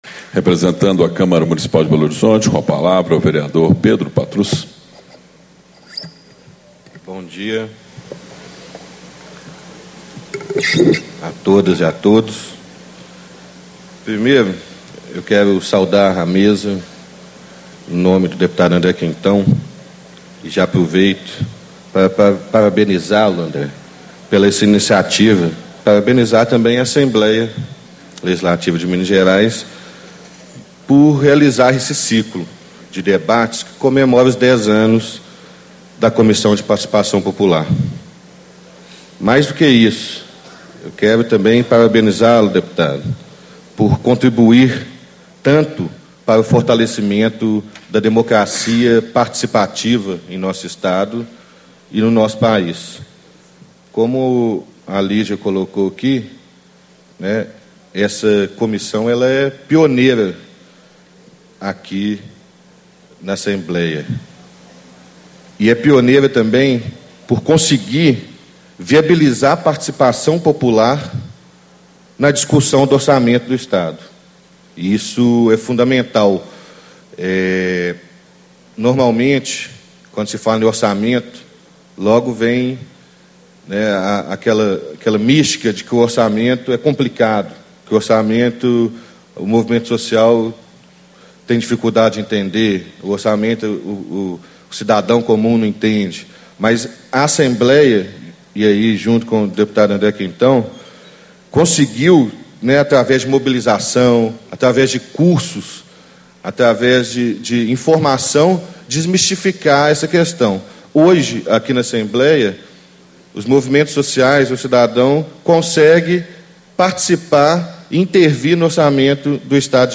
Abertura - Vereador Pedro Patrus - Representante da Câmara Municipal de Belo Horizonte
Ciclo de Debates 10 anos da Comissão de Participação Popular
Discursos e Palestras